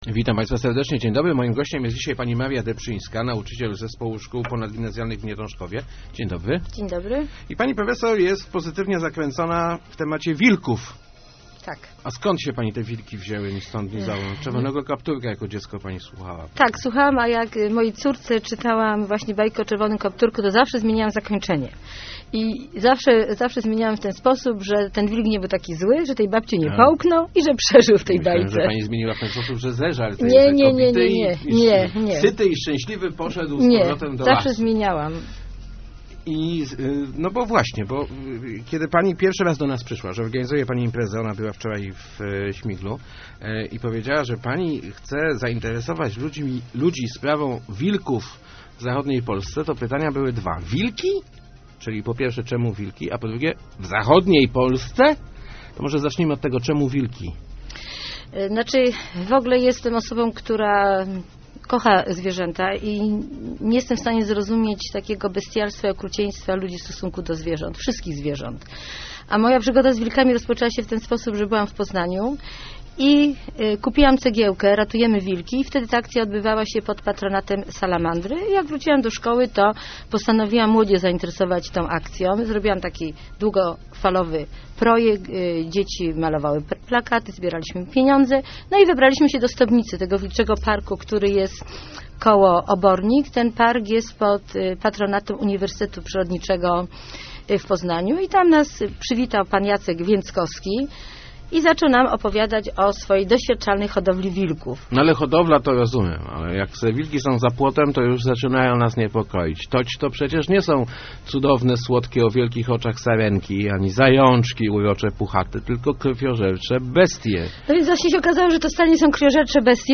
Start arrow Rozmowy Elki arrow Wilki?